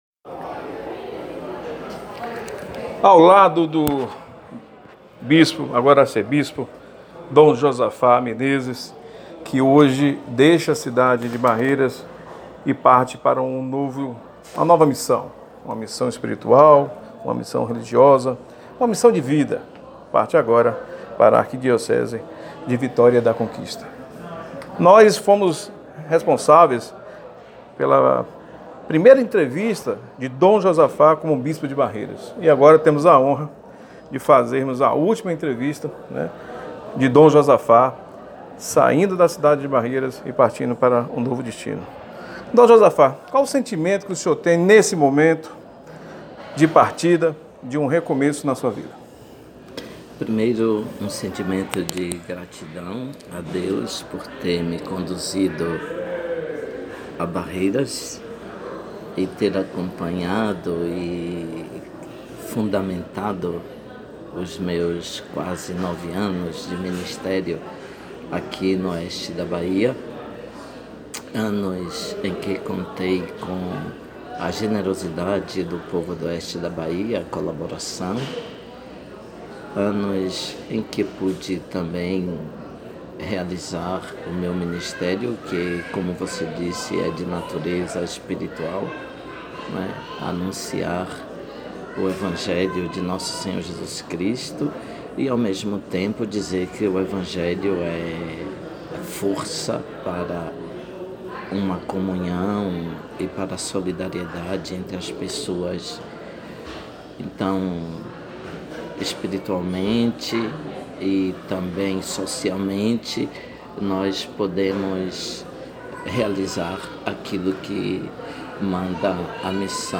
Entrevista exclusiva da despedida de Dom Josafá da Diocese de Barreiras, confira!